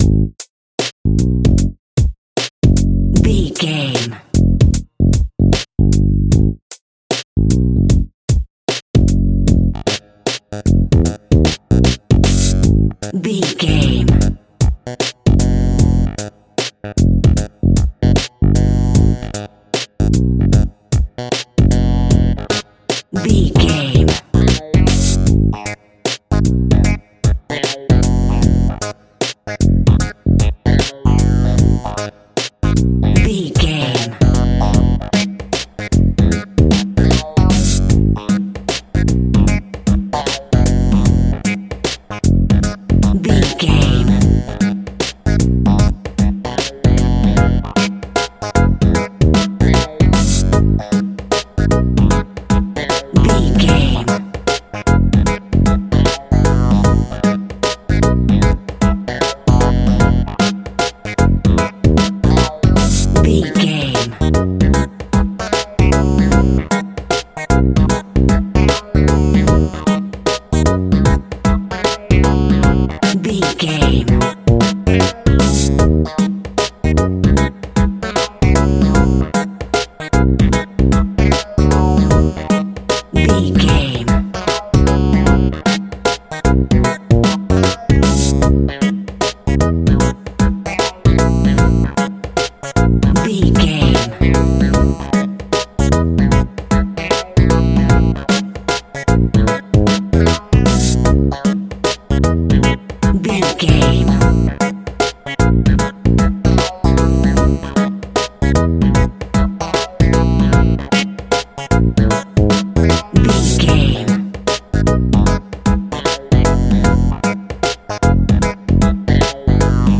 Aeolian/Minor
G#
funky house
disco funk
soul jazz
electric guitar
bass guitar
drums
hammond organ
fender rhodes
percussion